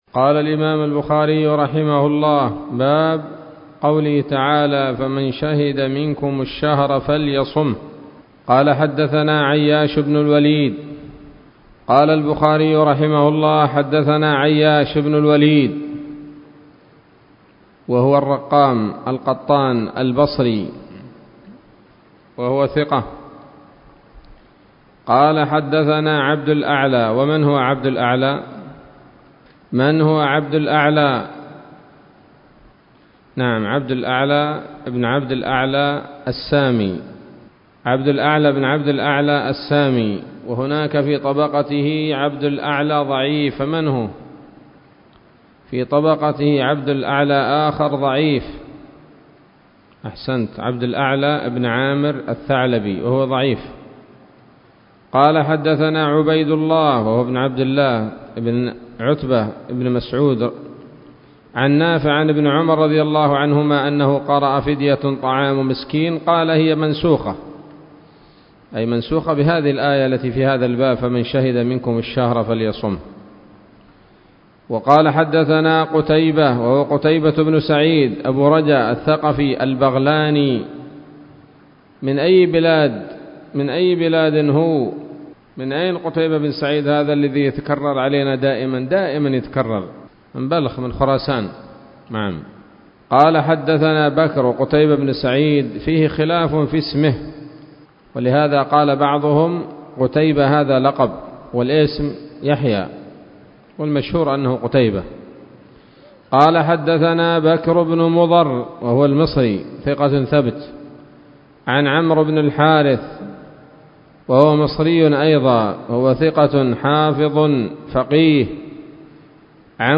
الدرس الثالث والعشرون من كتاب التفسير من صحيح الإمام البخاري